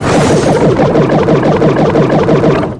bb_roll_01.wav